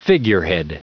Prononciation du mot figurehead en anglais (fichier audio)
Prononciation du mot : figurehead